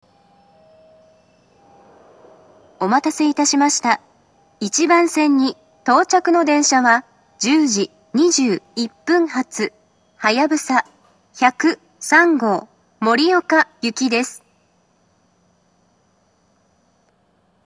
２０２１年２月上旬頃には、自動放送が合成音声に変更されました。
１番線到着放送